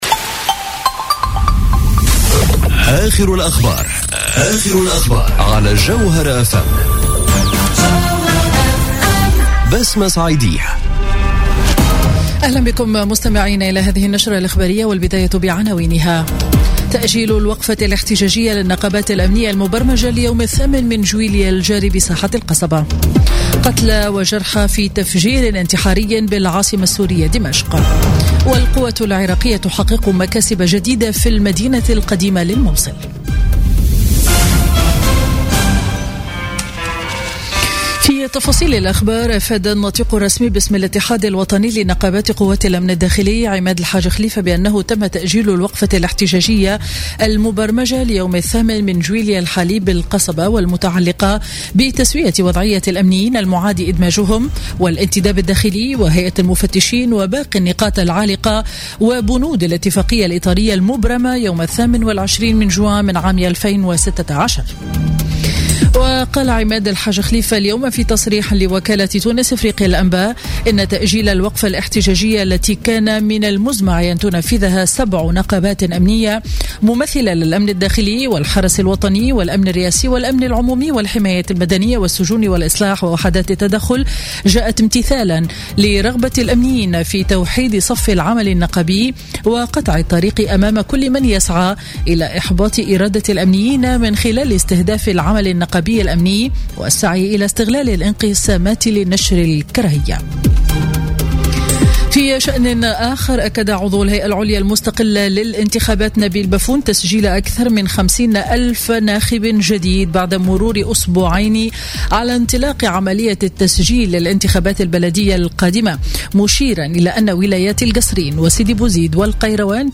نشرة أخبار منتصف النهار ليوم الأحد 2 جويلية 2017